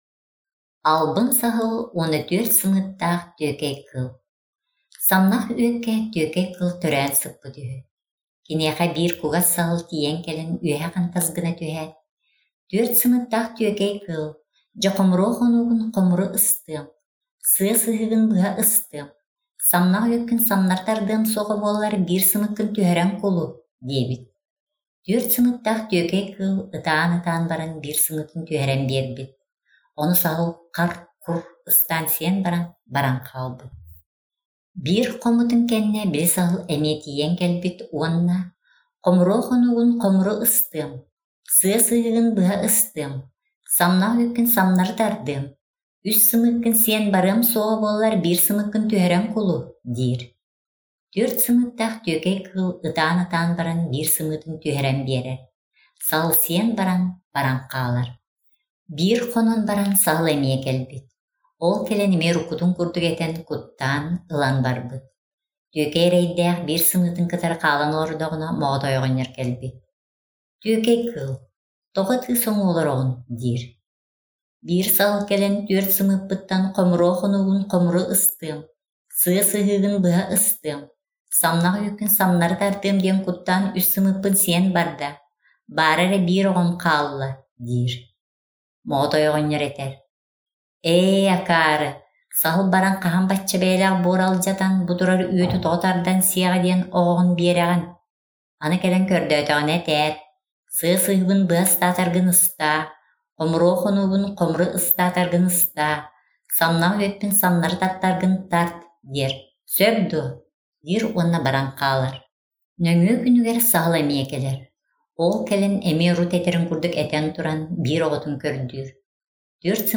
Аудиокниги (Истиҥ, ааҕыы), Год народного искусства